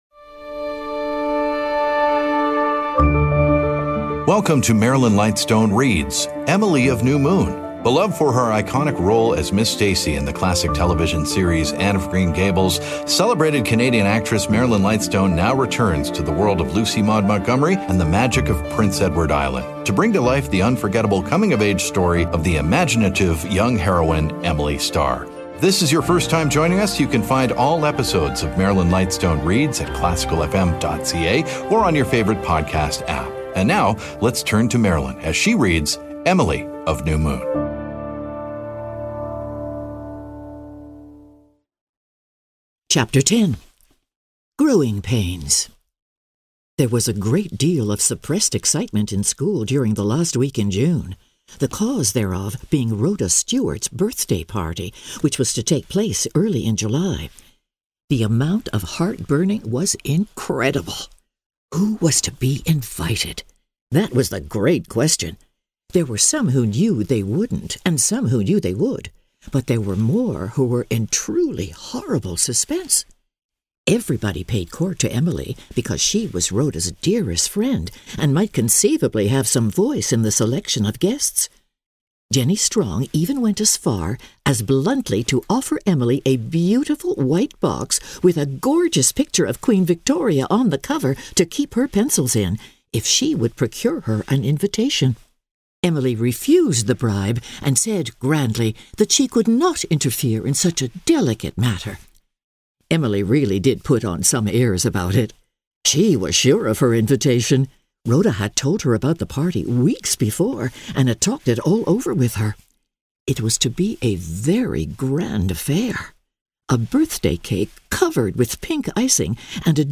Known for her roles on stage and screen, as well as her daily radio program "Nocturne" on The New Classical FM, acclaimed actress Marilyn Lightstone now brings classic literature to life with dramatic readings.
… continue reading 329 episodes # Theater # Arts # Marilyn Lightstone # Zoomer Podcast Network # Society # Audio Drama # Vanity Fair